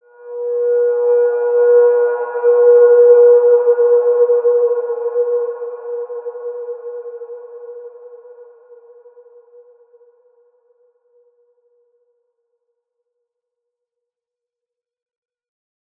Large-Space-B4-p.wav